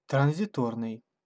Ääntäminen
IPA: [pa.sa.ʒe]